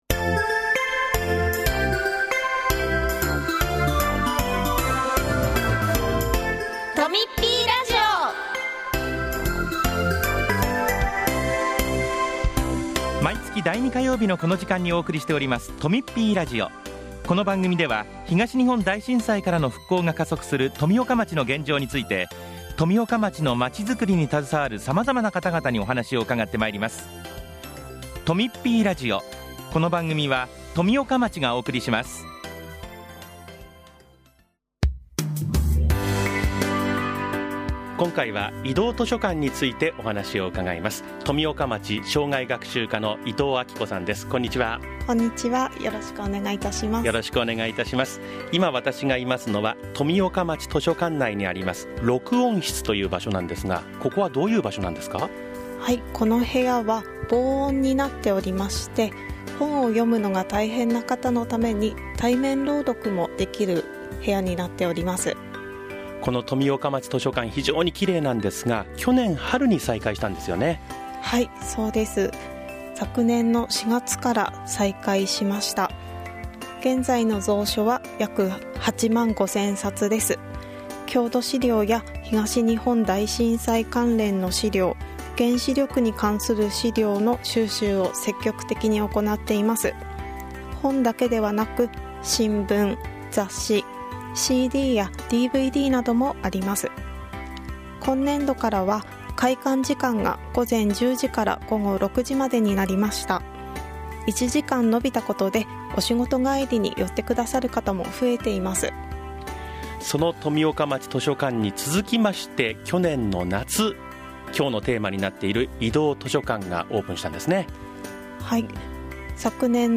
今回は、図書館司書による「移動図書館」についてです。その他、町からのお知らせもあります。